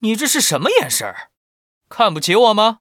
文件 文件历史 文件用途 全域文件用途 Timothy_amb_01.ogg （Ogg Vorbis声音文件，长度2.8秒，89 kbps，文件大小：31 KB） 文件说明 源地址:游戏解包语音 文件历史 点击某个日期/时间查看对应时刻的文件。 日期/时间 缩略图 大小 用户 备注 当前 2019年1月24日 (四) 04:34 2.8秒 （31 KB） 地下城与勇士  （ 留言 | 贡献 ） 分类:蒂莫西(地下城与勇士) 分类:地下城与勇士 源地址:游戏解包语音 您不可以覆盖此文件。